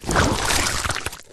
Blood_Suck1.wav